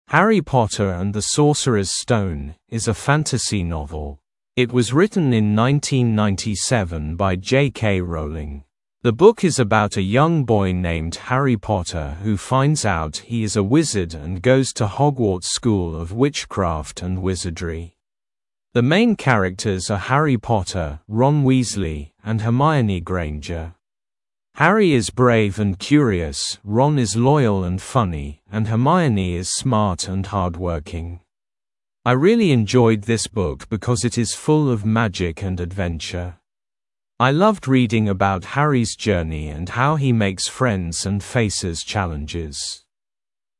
Произношение:
[“Хэри Поттер энд зэ Сосэрэрз Стоун” из э фэнтэзи новэл.